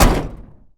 carKick1.ogg